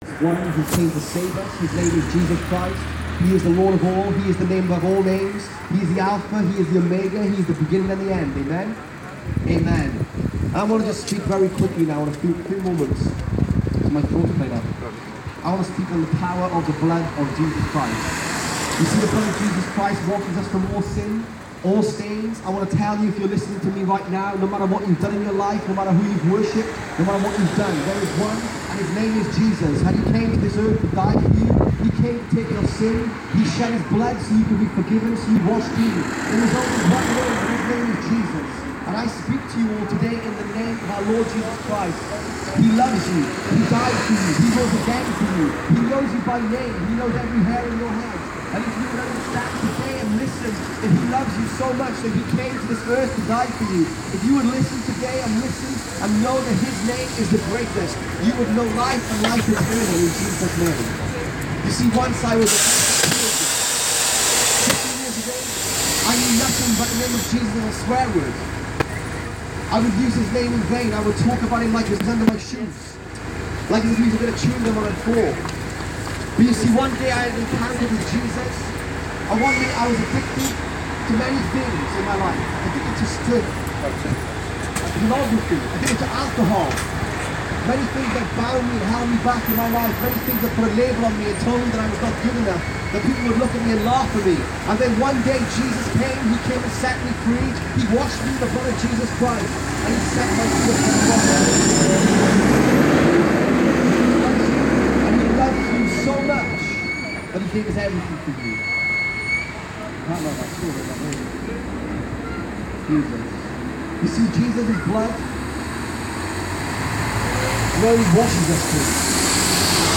A street preacher on Cowley Road, Oxford.